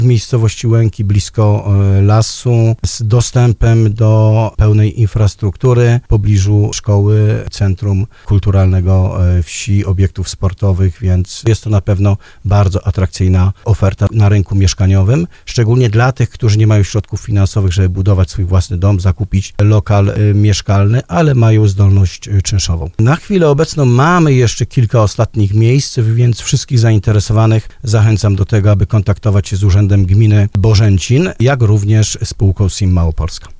Wójt Janusz Kwaśniak mówił na antenie RDN Małopolska, że są jeszcze wolne mieszkania i chętni mogą się zgłaszać.